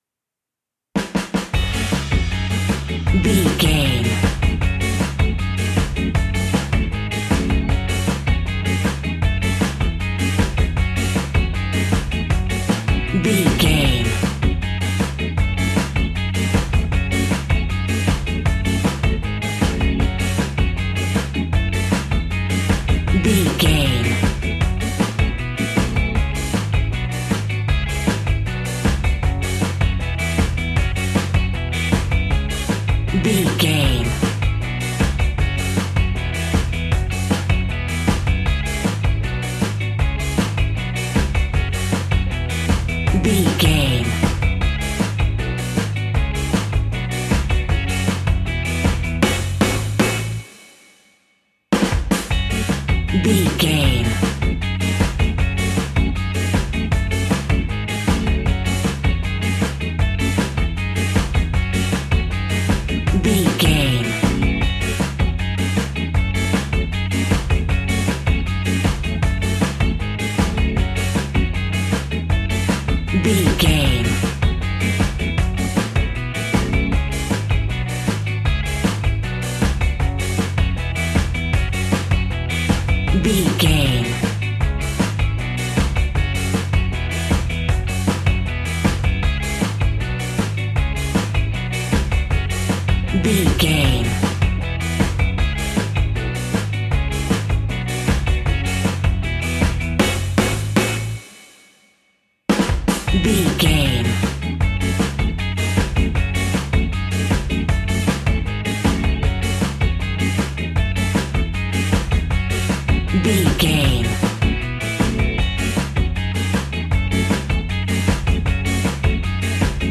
Classic reggae music with that skank bounce reggae feeling.
Aeolian/Minor
F#
reggae
laid back
chilled
off beat
drums
skank guitar
hammond organ
percussion
horns